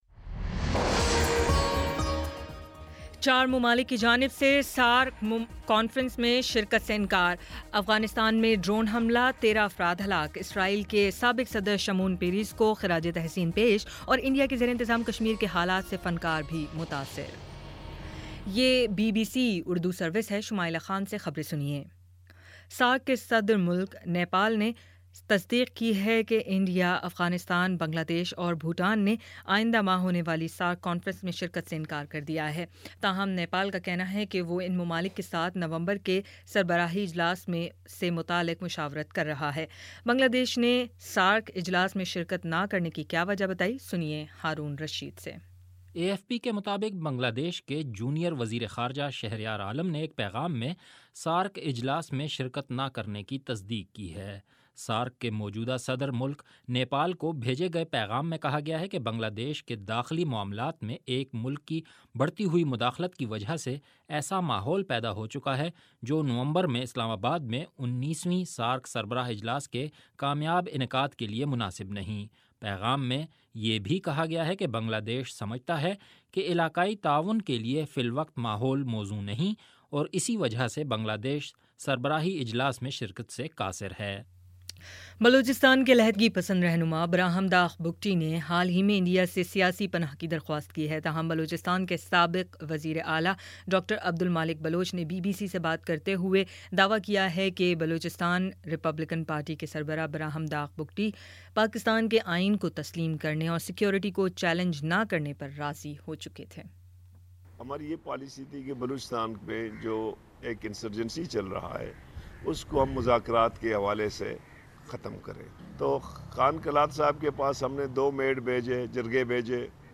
ستمبر 28 : شام پانچ بجے کا نیوز بُلیٹن